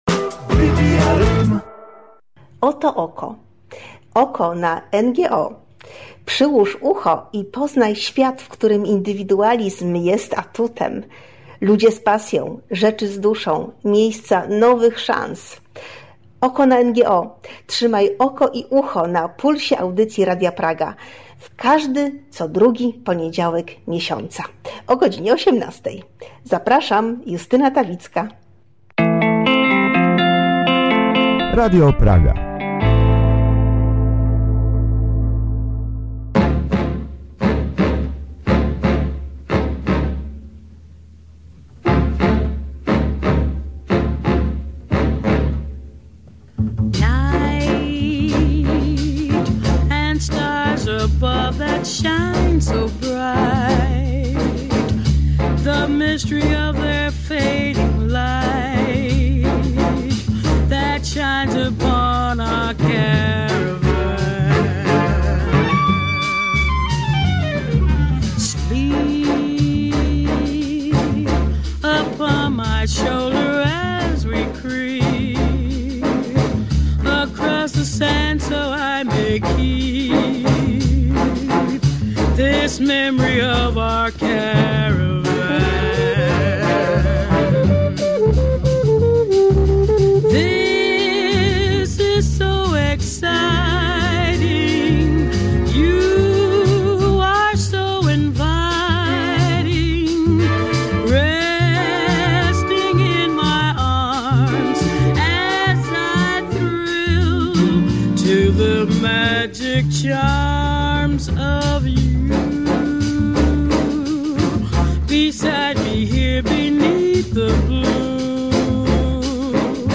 PS: Przepraszamy za słabą słyszalność naszego gościa na początku rozmowy.